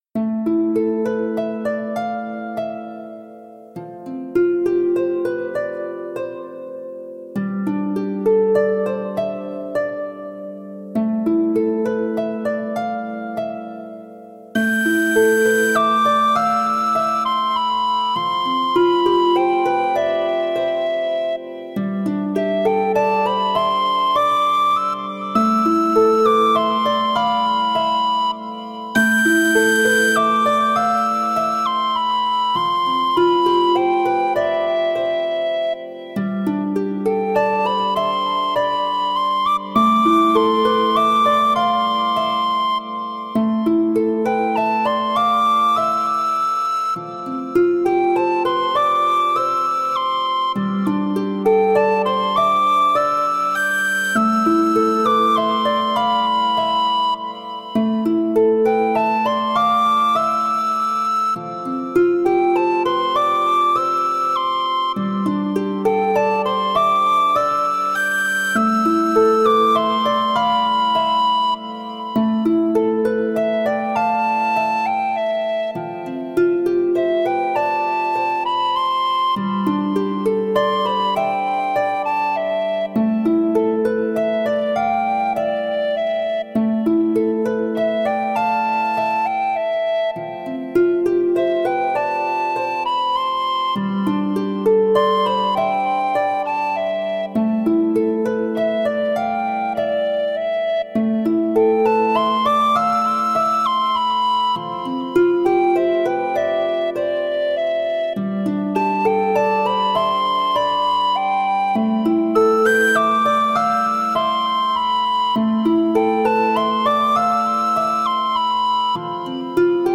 ケルティックな雰囲気の曲です。【BPM100】